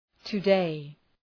{tə’deı}